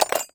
door_lock_fail_04.wav